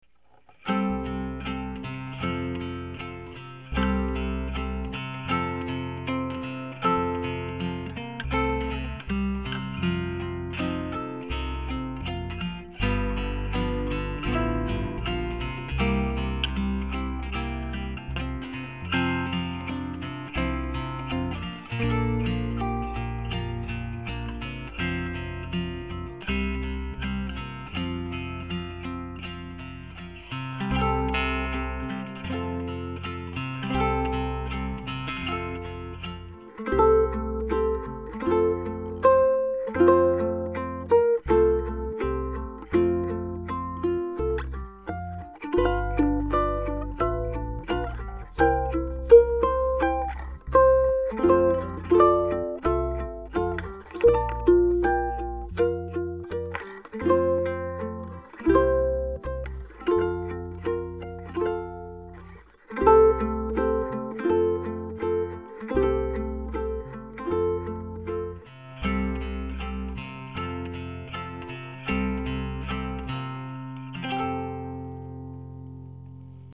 This hymn, Ke Aloha O Ka Haku, was written in March 1895 after she was imprisoned for alleged knowledge of a plot to return her to the throne.